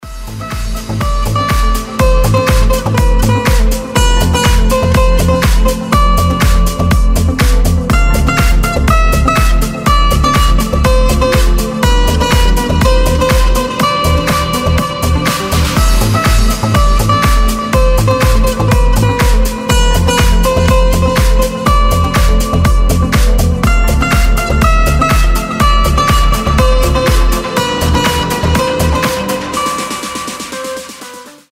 • Качество: 320, Stereo
гитара
deep house
без слов
красивая мелодия
Душевная мелодия с грустной гитарой